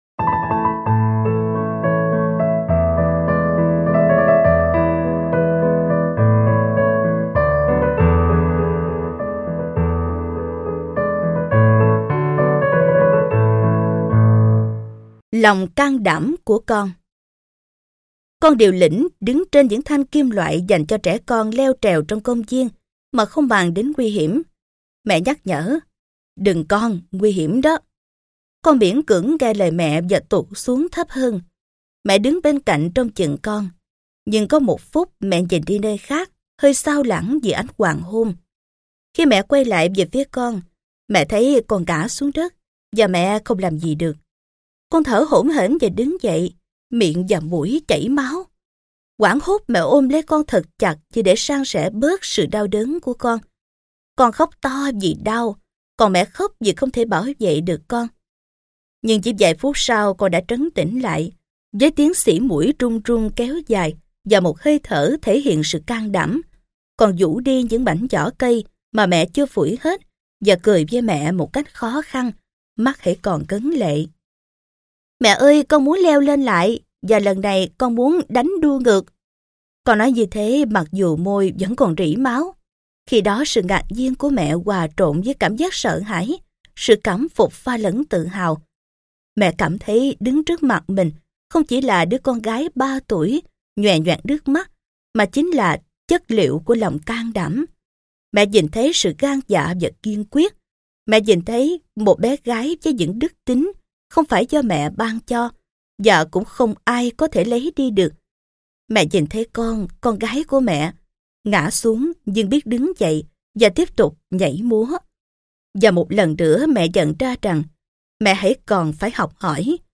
Sách nói Chicken Soup 9 - Vòng Tay Của Mẹ - Jack Canfield - Sách Nói Online Hay